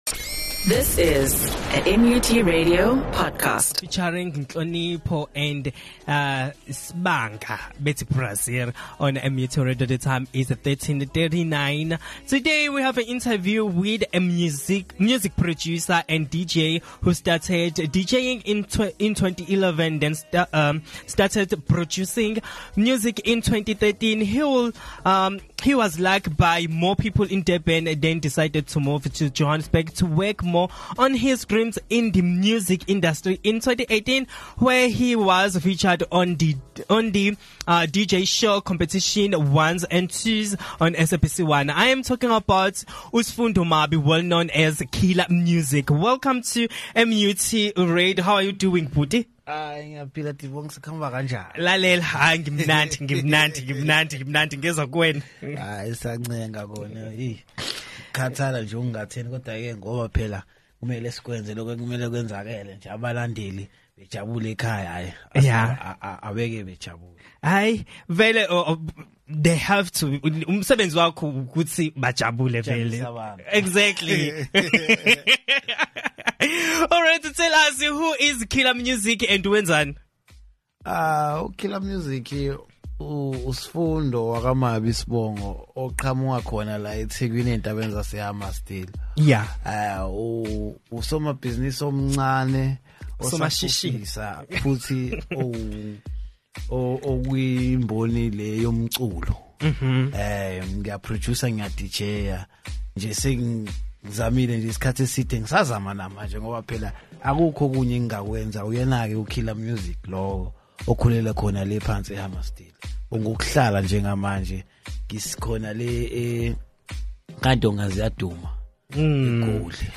Top30 Chart Show